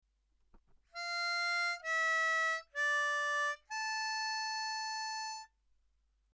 It’s a Sea Shanty sung by Sailors and Pirates.
We have chopped the tune up into small chunks to help you.